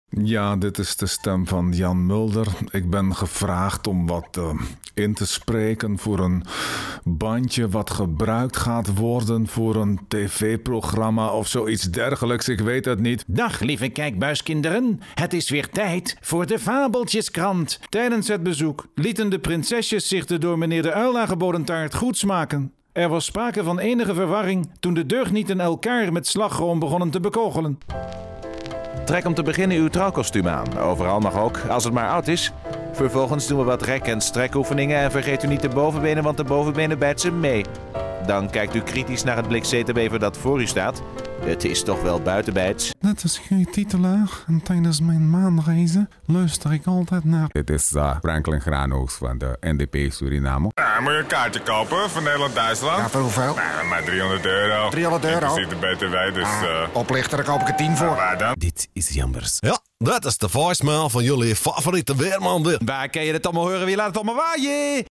Stimme mit eigener studio und ISDN-APT.
Sprechprobe: Sonstiges (Muttersprache):
Excellent voice-over for trailers/commercials/films & impersonations in Dutch and English.